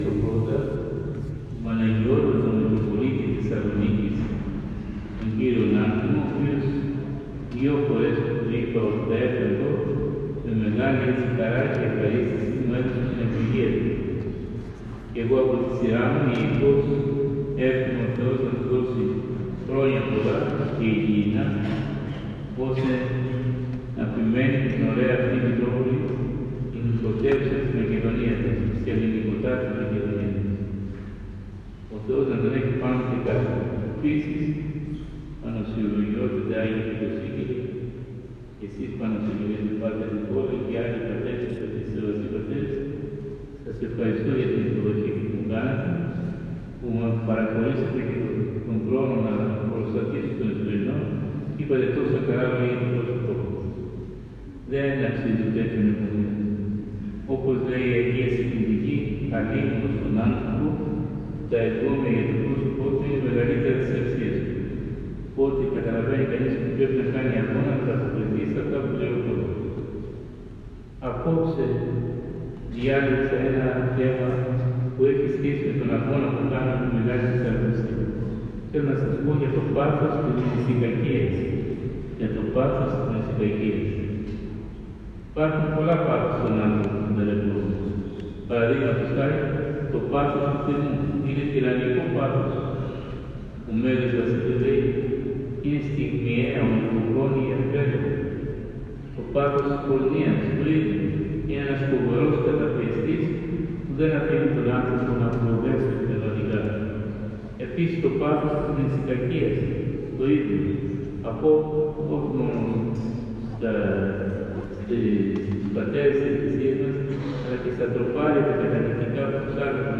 Πραγματοποιήθηκε, σήμερα, Κυριακή της Σταυροπροσκυνήσεως ο Δ’ Κατανυκτικός Εσπερινός στον Ιερό Ναό Μεταμορφώσεως του Σωτήρος (Δελφών και Μιαούλη) Θεσσαλονίκης. Της ακολουθίας του Εσπερινού χοροστάτησε ο Σεβ. Μητροπολίτης Εδέσσης Πέλλης και Αλμωπίας, κ. Ιωήλ, ο οποίος και ήταν ο ομιλητής, αναπτύσσοντας το θέμα “Το πάθος της μνησικακίας”.